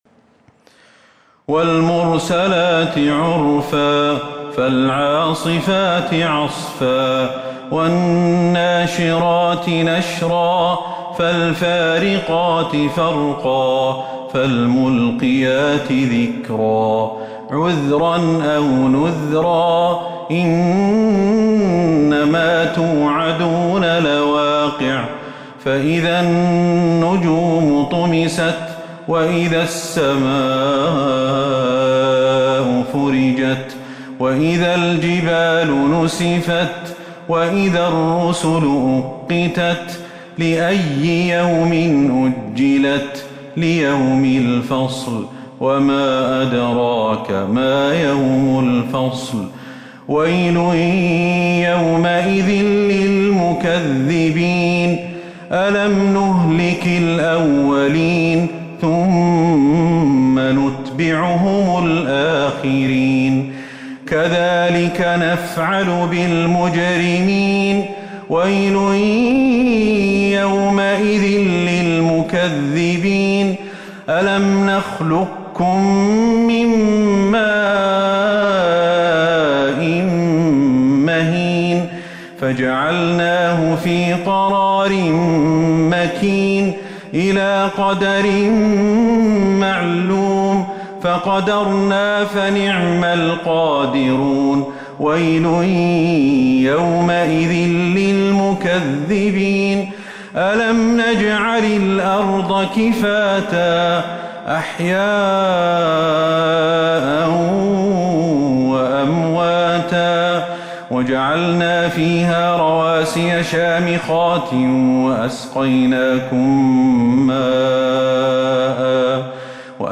سورة المرسلات Surat Al-Mursalat من تراويح المسجد النبوي 1442هـ > مصحف تراويح الحرم النبوي عام 1442هـ > المصحف - تلاوات الحرمين